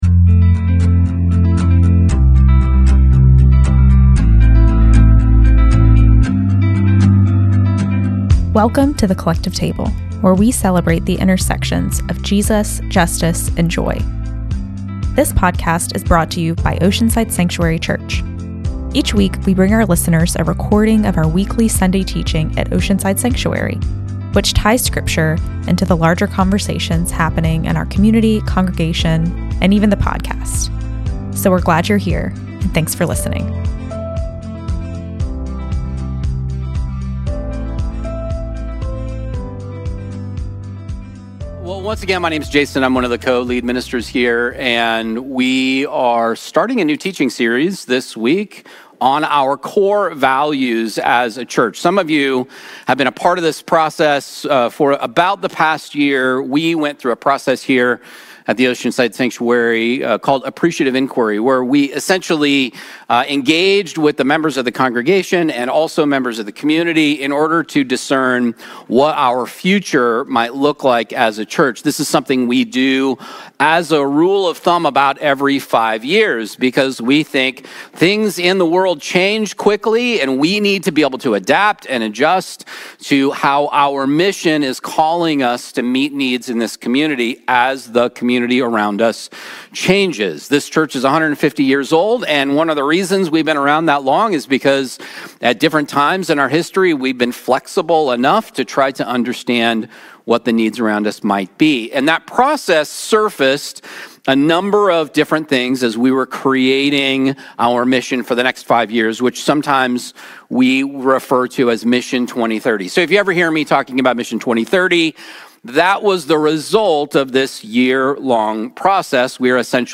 We begin a new teaching series on our five refined core values, starting with the first and most clear result: Authentic Belonging. Exploring the story of Zacchaeus in Luke 19, we look at how Jesus prioritized people over rules and how true belonging creates a feedback loop of safety, wholeness, and healing.